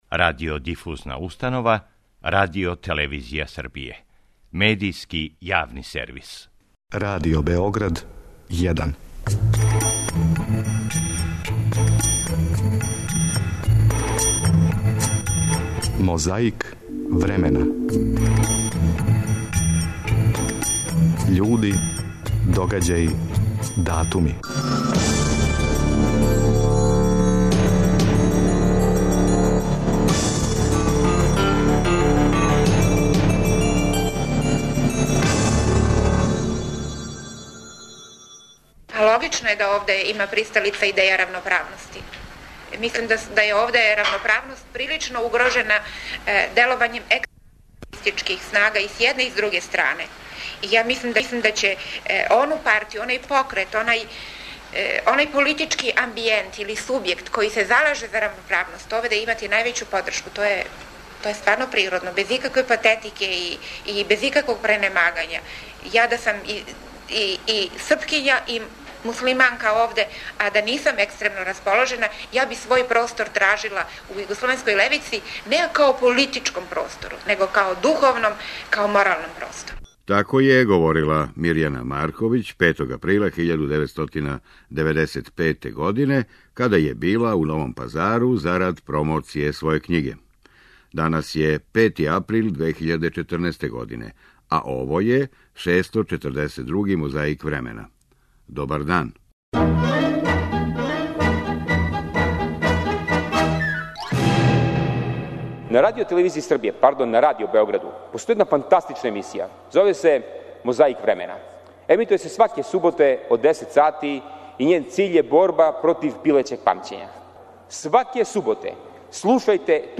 Ове суботе је отвара Мирјана Марковић. Чућете како је говорила 5. априла 1995. године када је била у Новом Пазару, зарад промоције своје књиге.